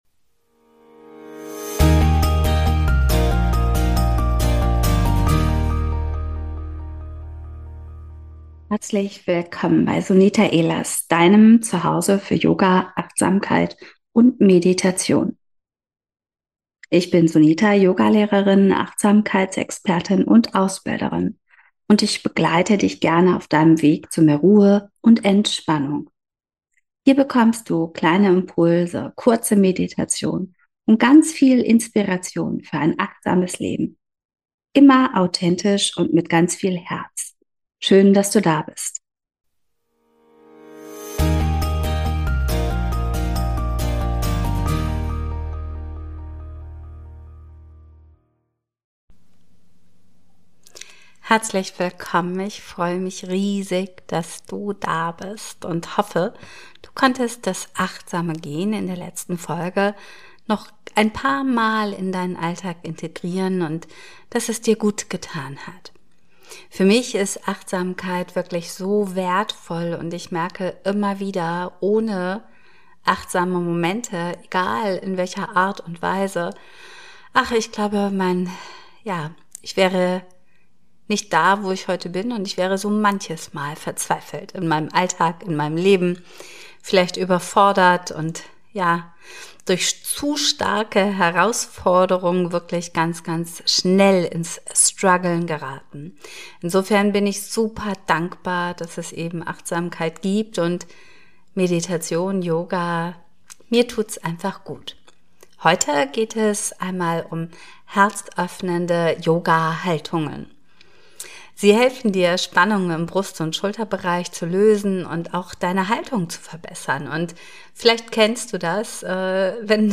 In dieser Folge führe ich dich durch sanfte herzöffnende Yoga-Übungen. Sie helfen dir, Spannungen im Brust- und Schulterbereich zu lösen, deine Haltung zu verbessern und emotional mehr Offenheit, Vertrauen und Leichtigkeit zu spüren.